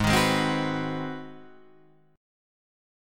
G#7#9 chord